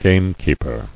[game·keep·er]